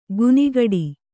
♪ gunigaḍi